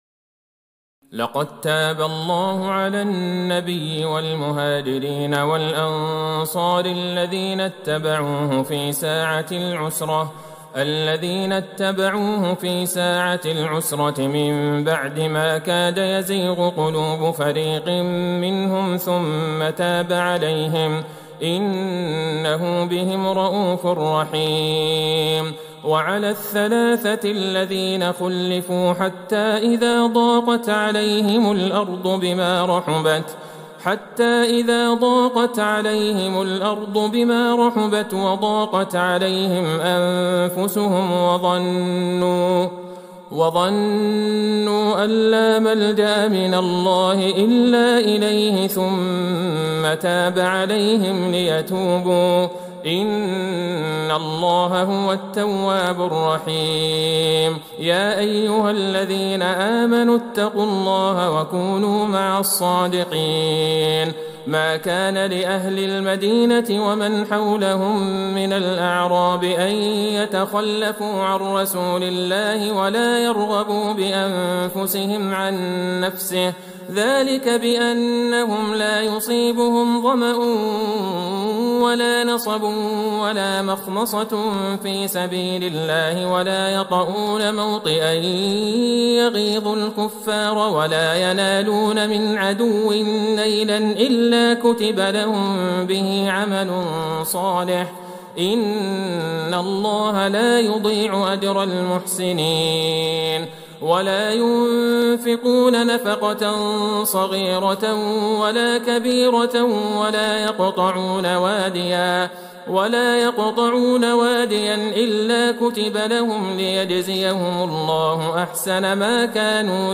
ليلة ١٤ رمضان ١٤٤١هـ من سورة التوبة { ١١٧-١٢٩ } ويونس { ١-٢٥ } > تراويح الحرم النبوي عام 1441 🕌 > التراويح - تلاوات الحرمين